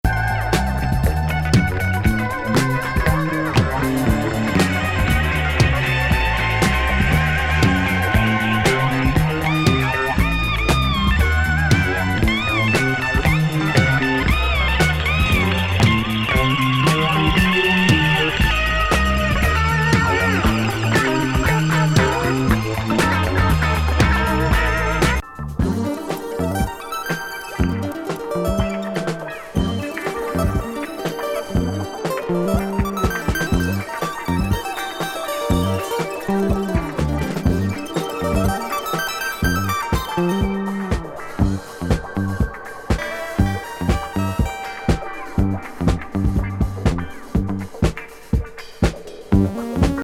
怪奇ジャズ・ファンク
変スリリング・ジャズ・ファンク